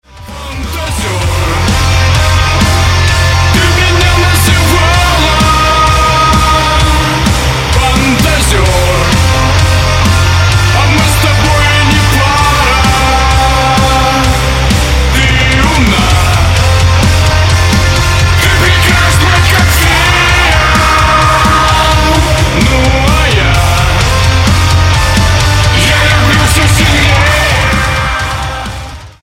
Громкие Рингтоны С Басами
Рок Металл Рингтоны